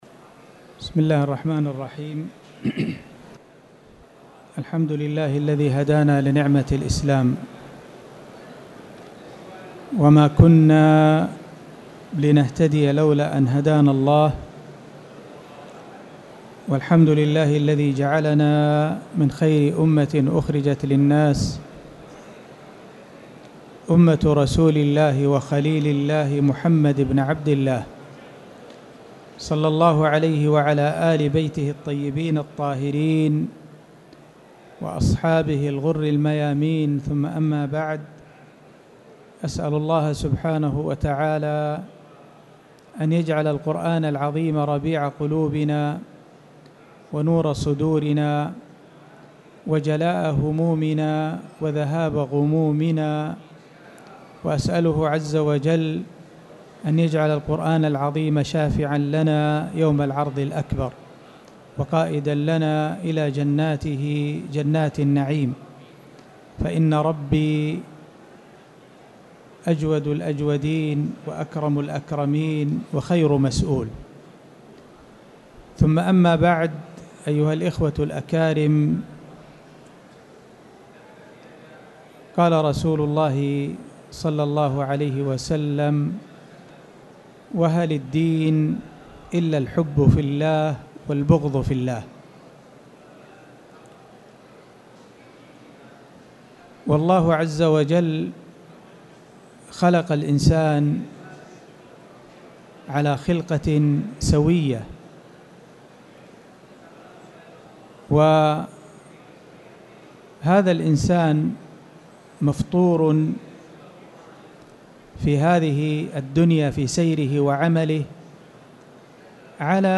تاريخ النشر ٩ جمادى الأولى ١٤٣٨ هـ المكان: المسجد الحرام الشيخ